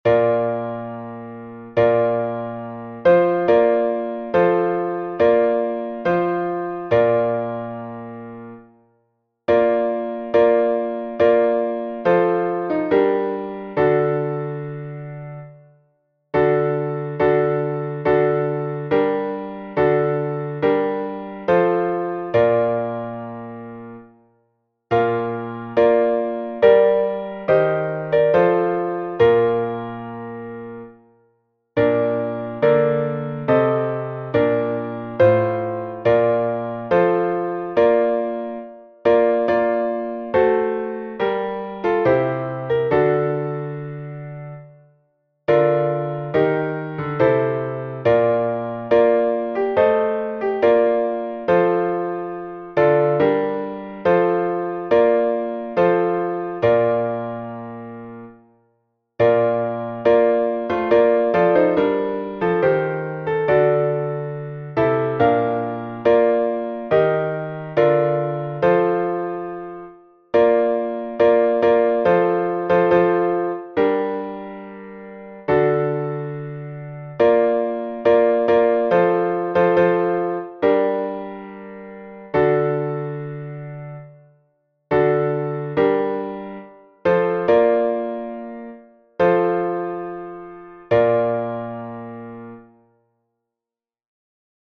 Key: B♭ Major